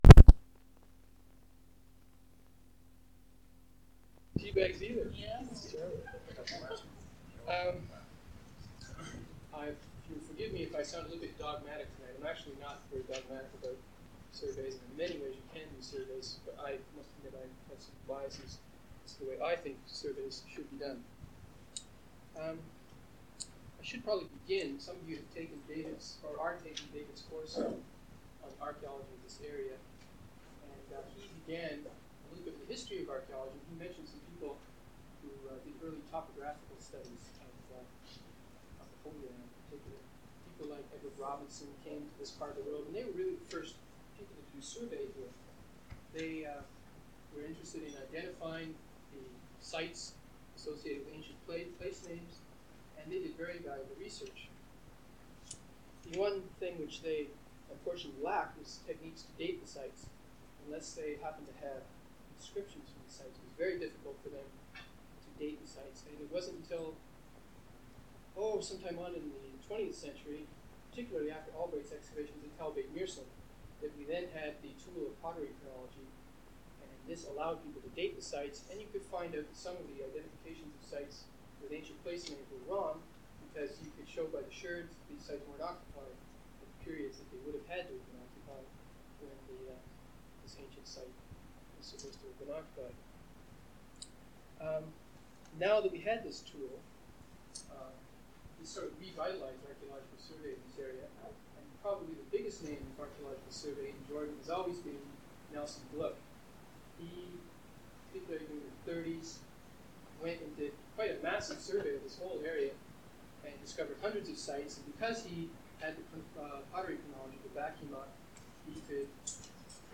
Format en audiocassette